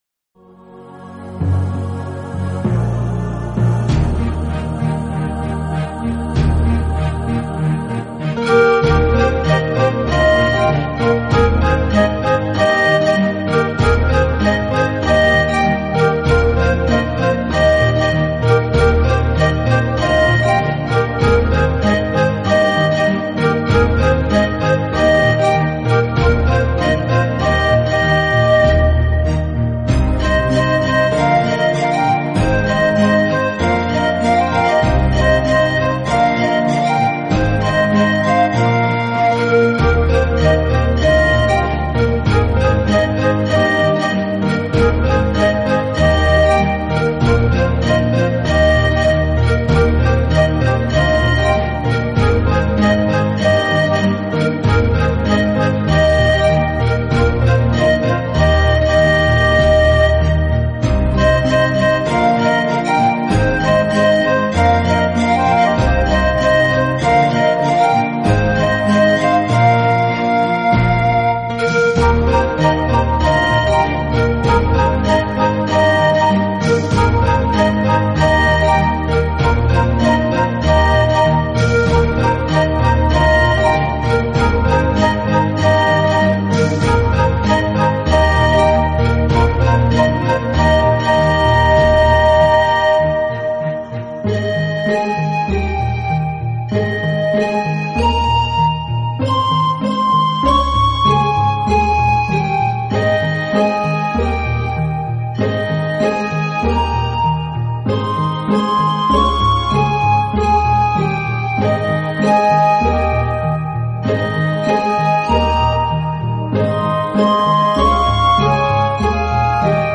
Style: Easy Listening
经典名曲的排萧重新演绎，排萧的演奏悠扬中
带有现代韵味，优质音色令人感觉出尘脱俗。